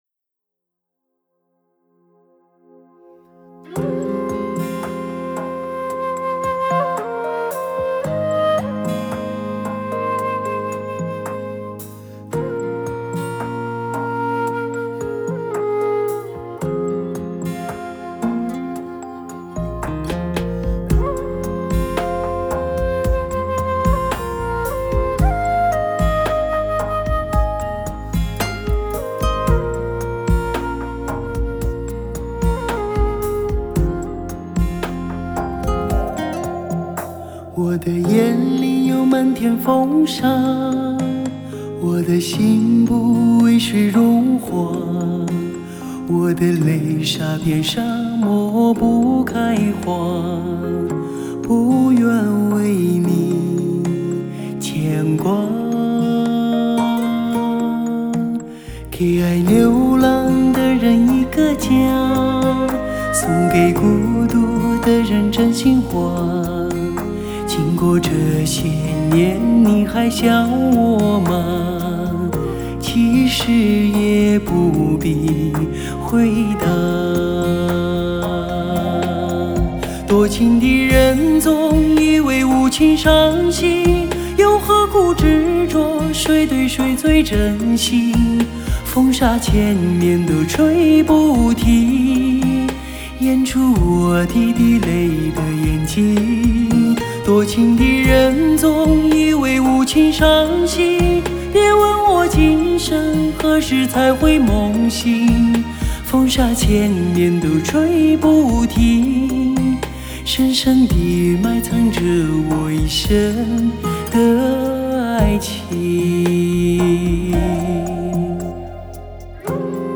类别: 摇滚